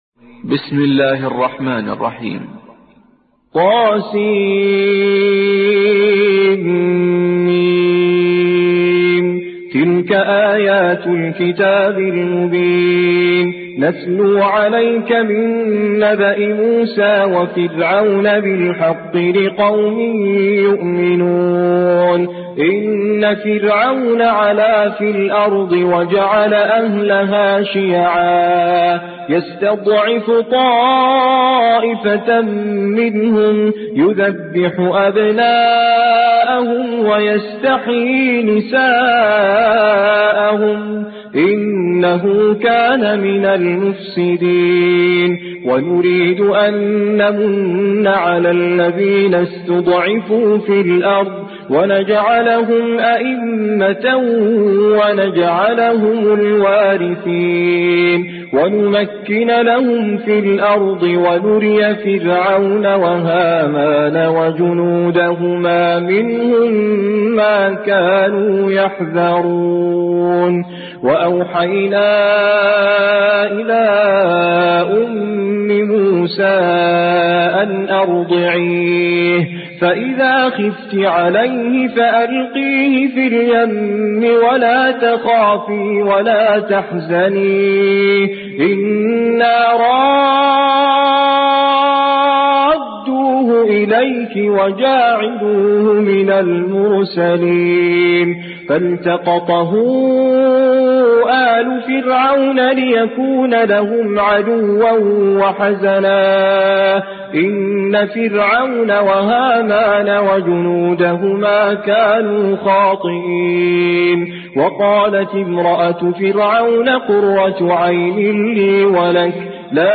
28. سورة القصص / القارئ